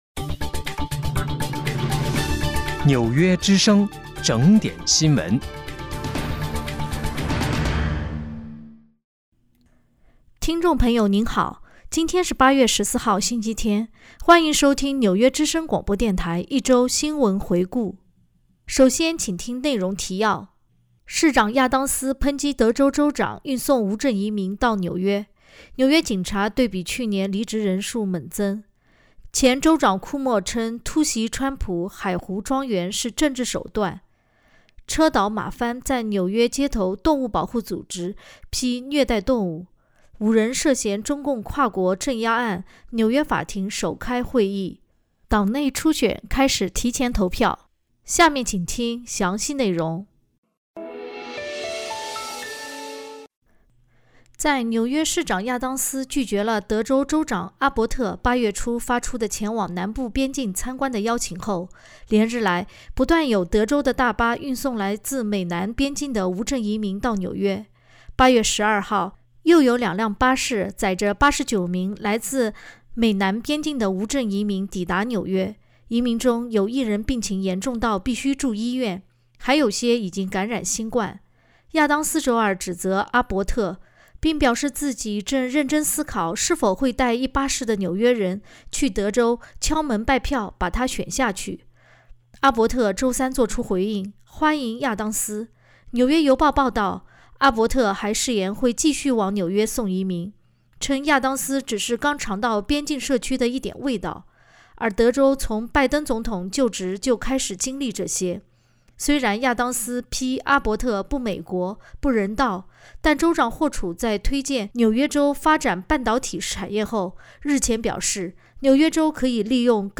8月14日（星期日）一周新闻回顾